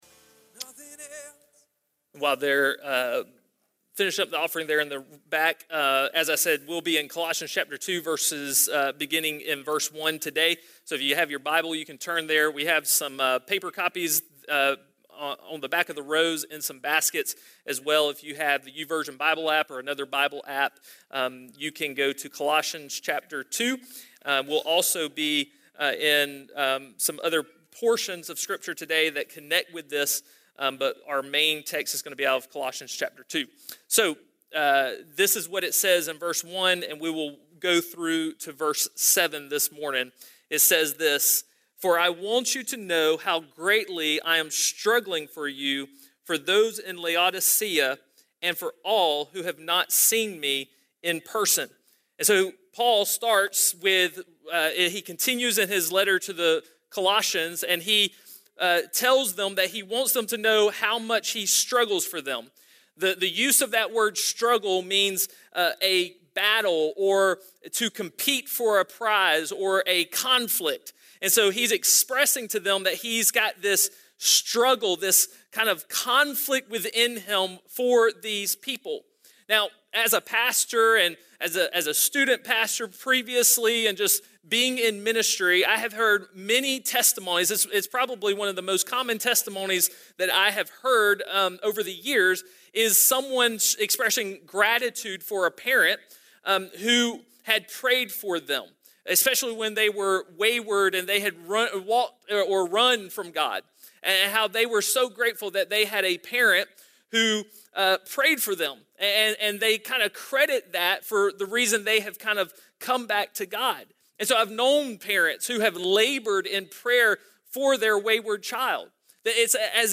A message from the series "Unhindered."